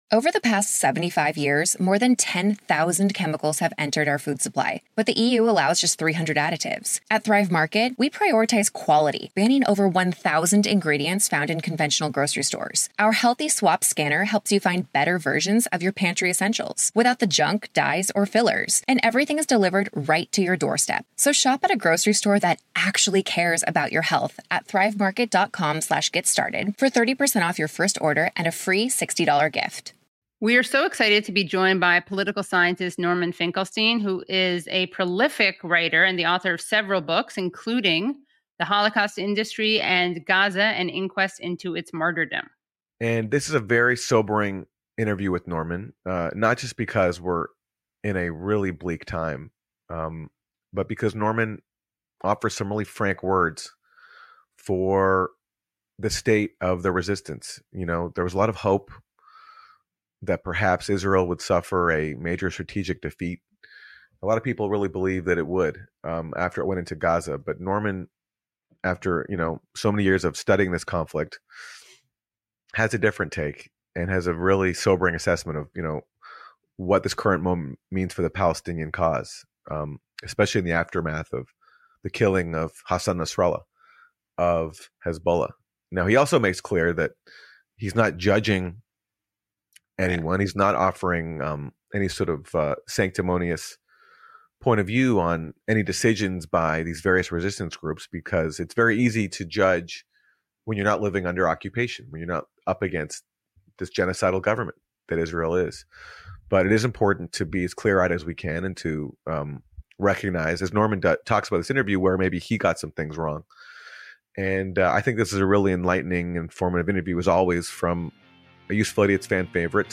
Watch our full interview with Norm Finkelstein from October 11 here: